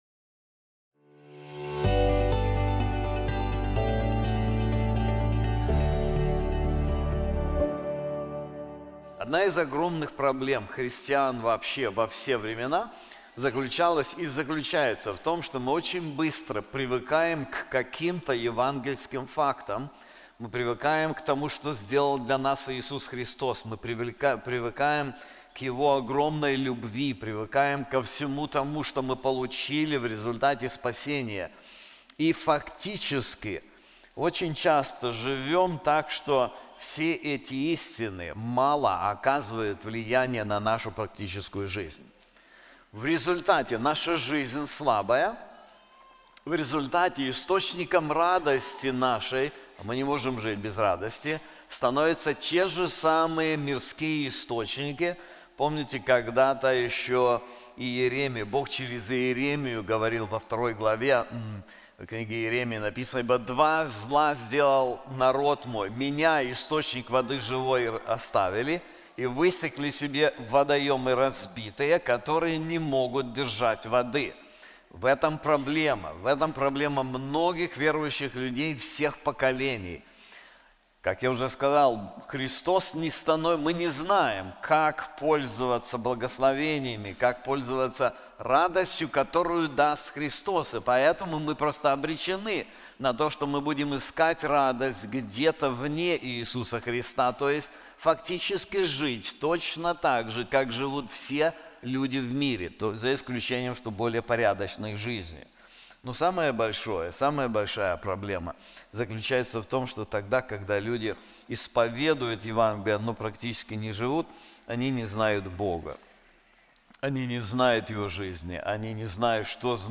This sermon is also available in English:The Gospel: The Power to Triumph in Hardship • Romans 8:31-39